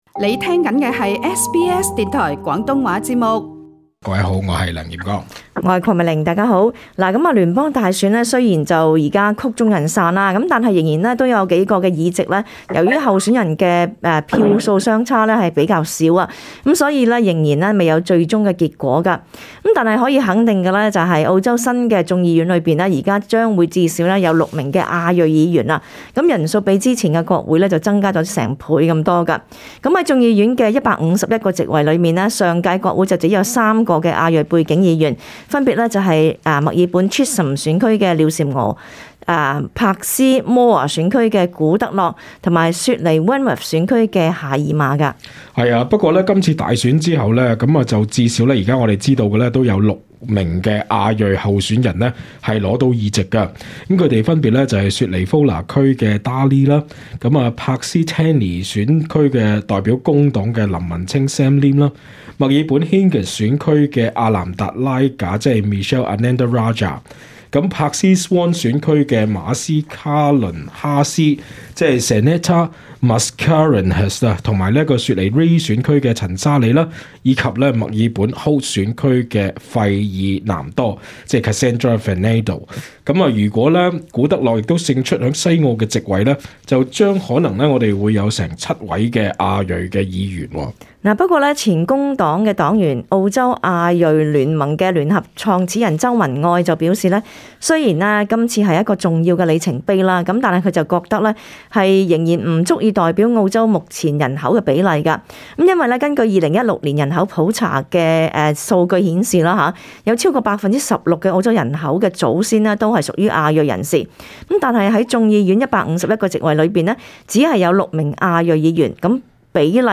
* 本節目 內嘉賓及聽 眾意見並不代表本台立場 READ MORE 亞裔新任議員競爭資格遭質疑 Dai Le正式回應 【專訪】澳洲華裔兒童文學家：冀以故事書抗擊種族歧視 亞裔長者長龍每周現身 義工憂免費食物包服務「難以維持」 瀏覽更多最新時事資訊，請登上 廣東話節目 Facebook 專頁 、 MeWe 專頁 、 Twitter 專頁 ，或訂閱 廣東話節目 Telegram 頻道 。